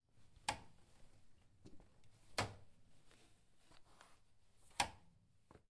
05后刹车杆